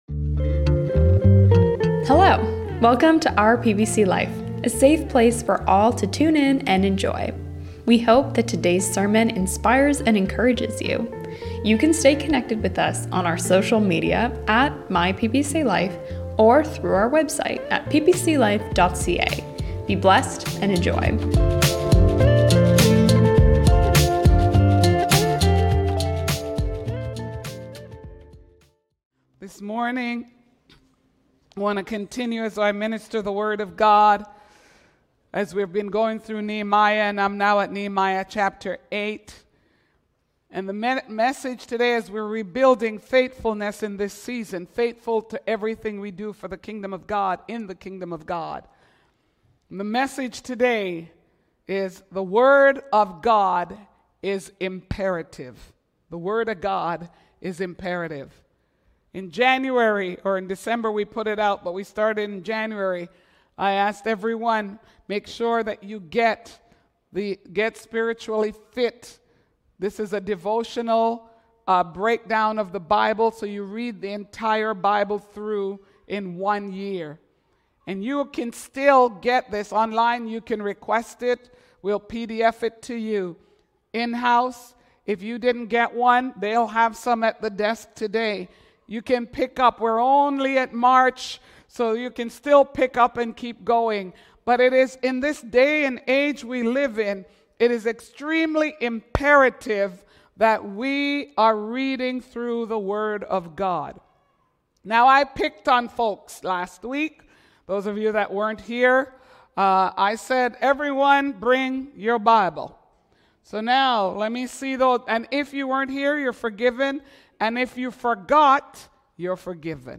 Don't miss this encouraging sermon!